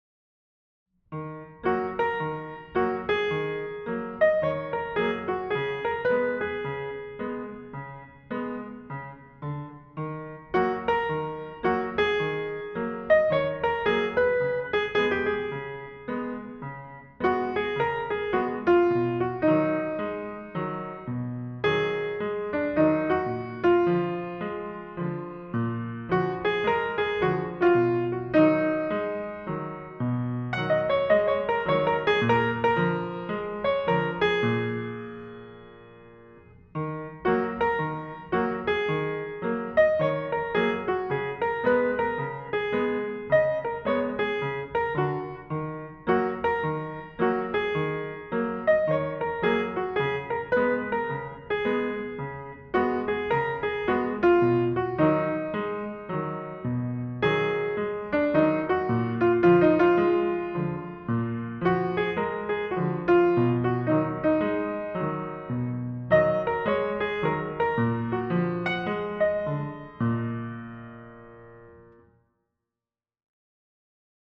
Anyway, #6 is a jaunty little tune, suitable for a sunny Sunday like today.
piano-day-6.mp3